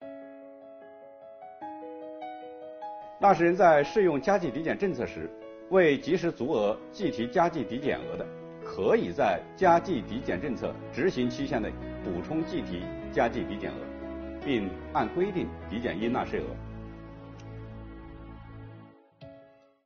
本期课程由国家税务总局货物和劳务税司副司长刘运毛担任主讲人，对2022年服务业领域困难行业纾困发展有关增值税政策进行详细讲解，方便广大纳税人更好地理解和享受政策。今天我们来学习：加计抵减政策延续实施后，前期未及时足额计提加计抵减额如何处理？